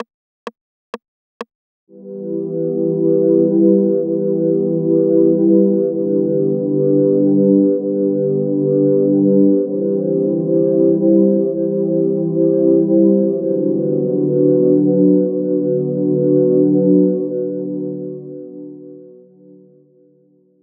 String (warm).wav